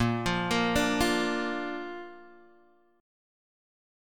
A# Chord